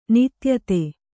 ♪ nityate